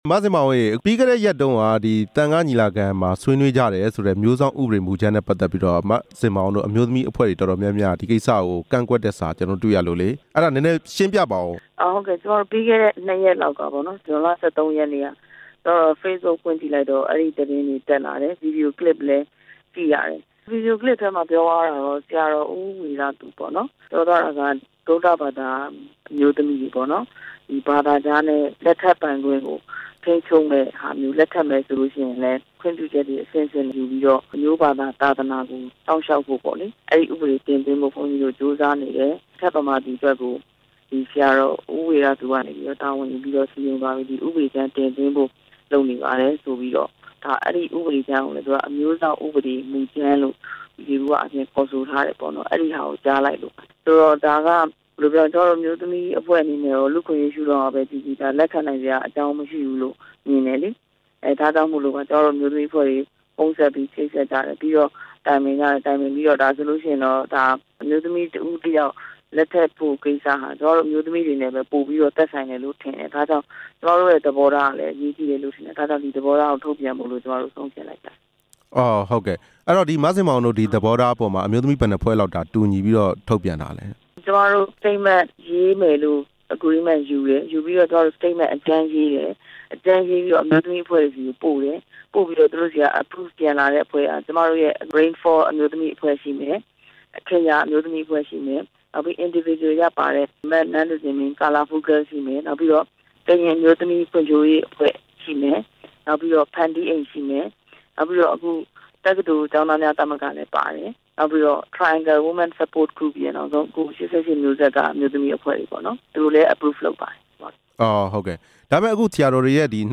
မဇင်မာအောင်နဲ့ မေးမြန်းချက်